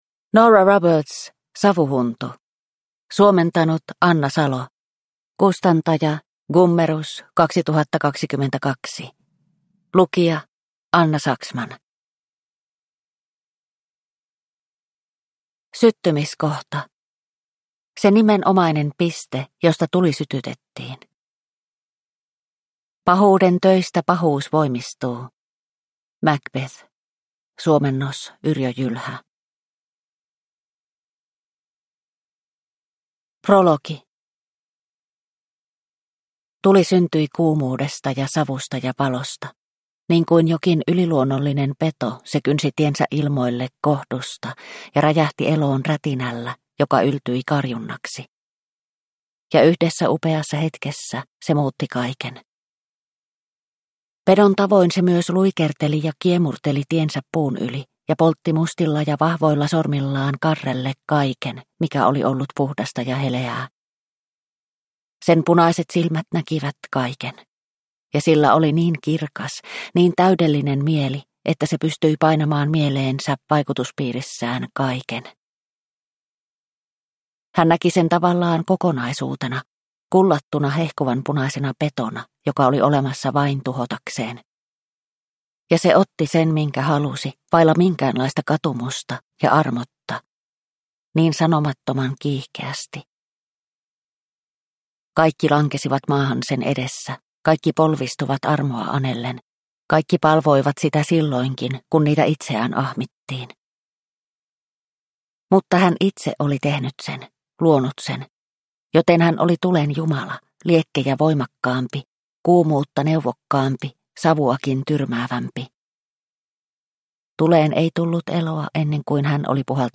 Savuhuntu – Ljudbok – Laddas ner